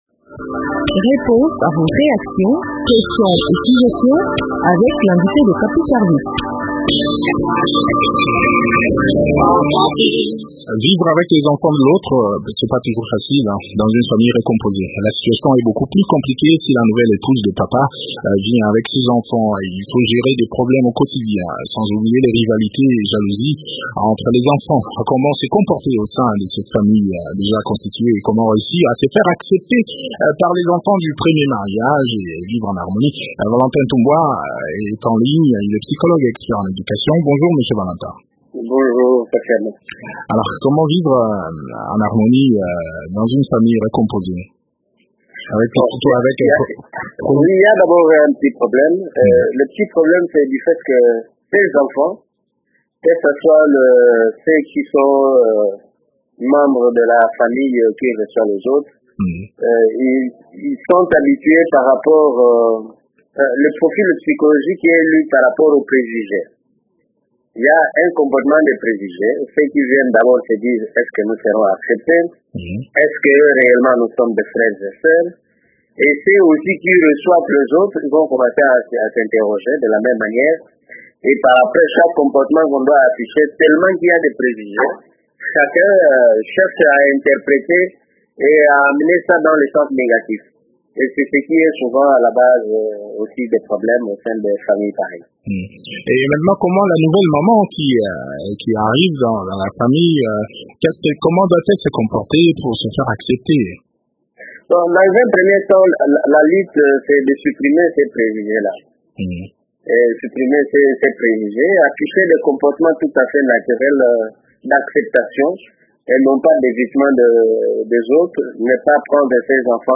psychologue et expert en éducation